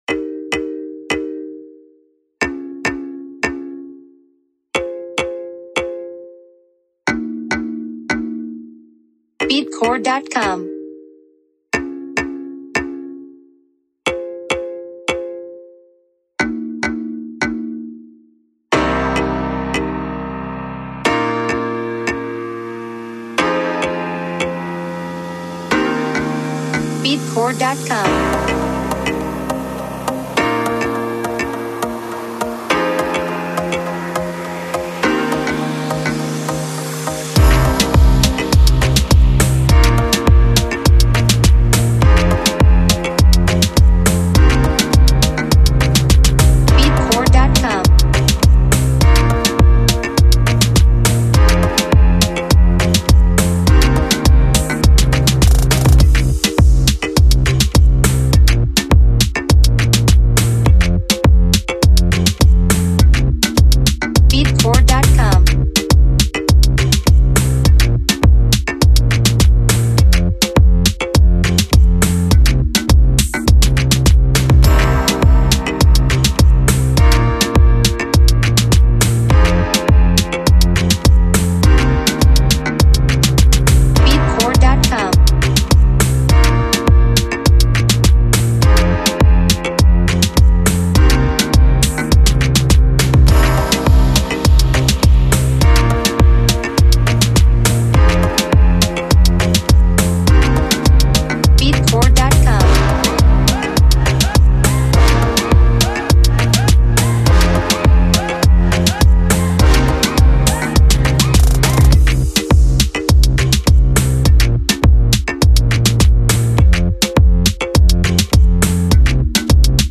Genre: Hip-Hop Reggaeton BPM: 103 Key: Cmin
Mood: Lover Hopeful Summer